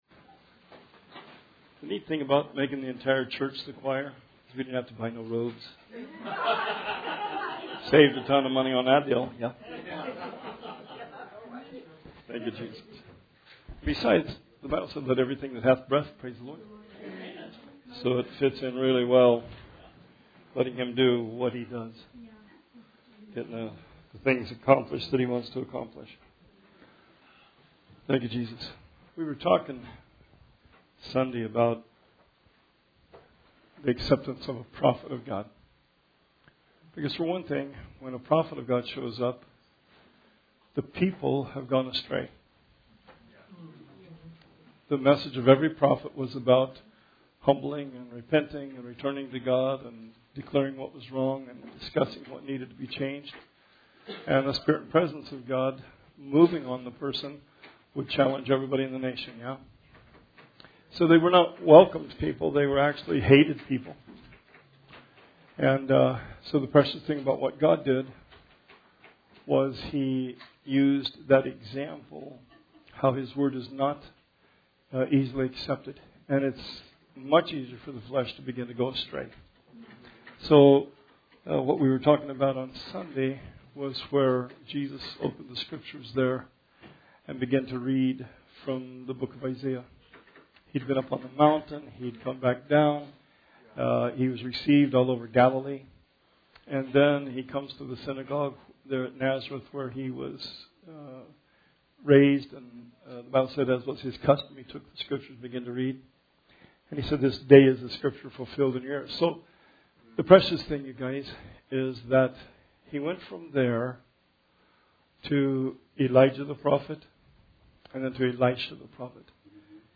Bible Study 11/13/19